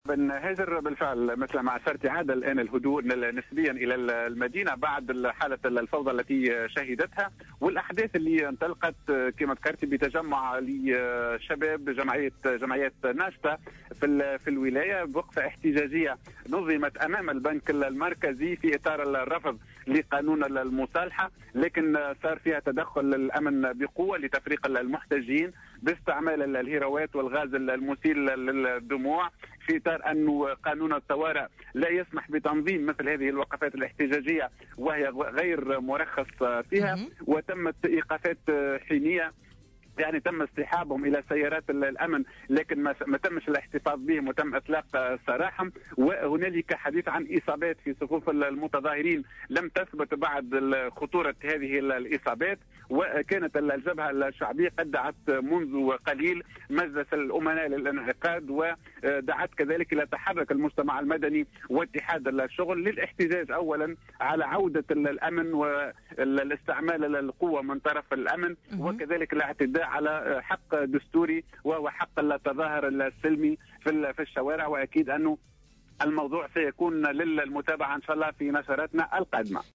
مراسلنا في صفاقس